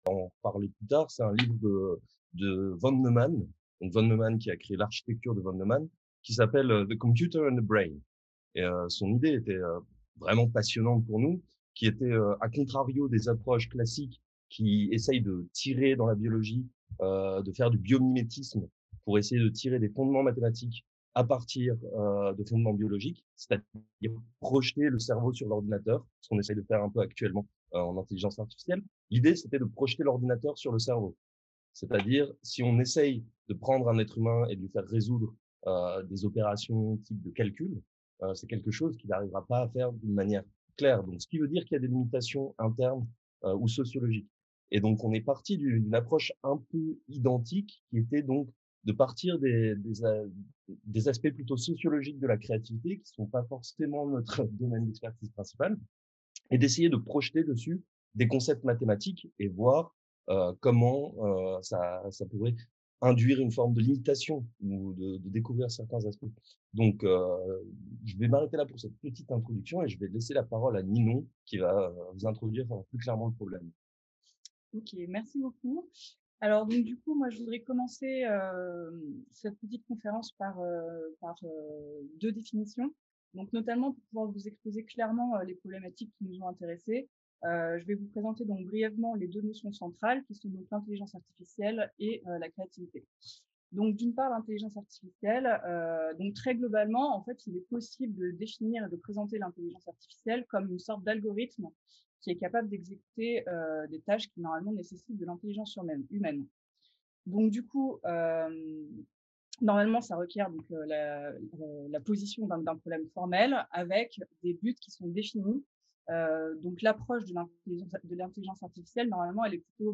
Dans le cadre de cette séance, nous allons pouvoir découvrir les divers projets de l’équipe ACIDS et entendre quelques œuvres composées à l’aide de l’intelligence artificielle.